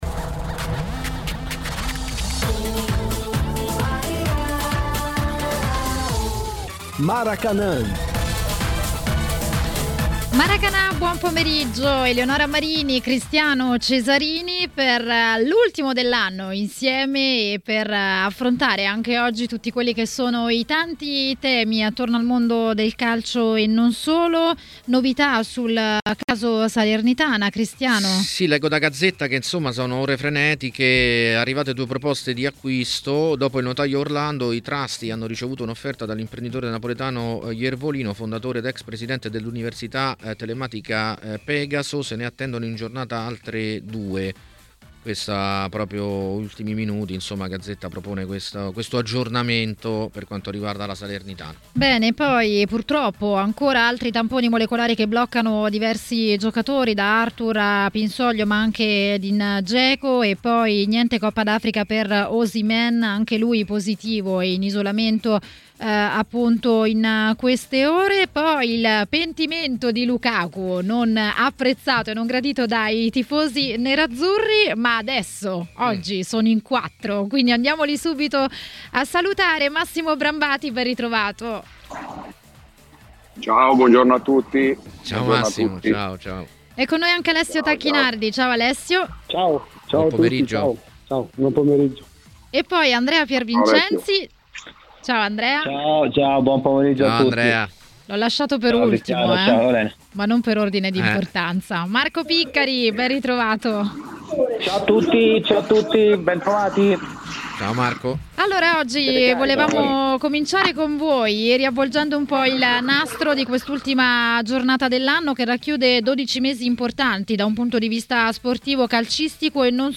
A parlare in diretta ai microfoni di TMW Radio, durante Maracanà